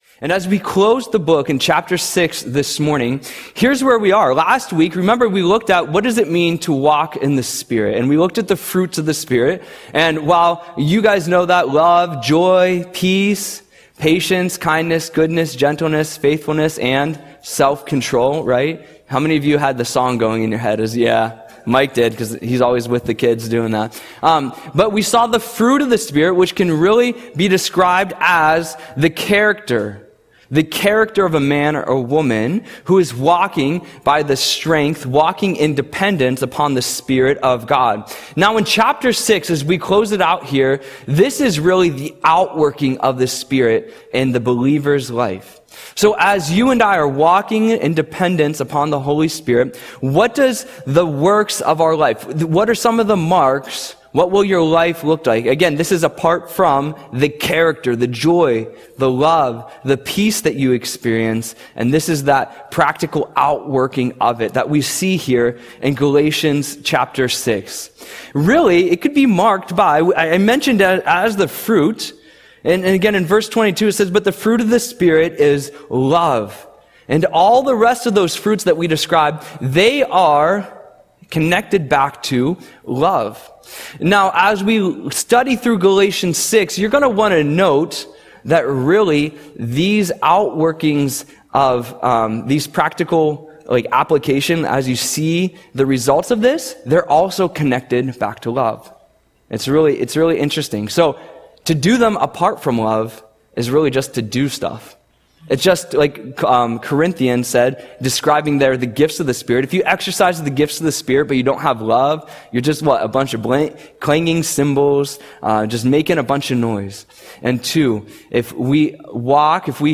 Audio Sermon - July 6, 2025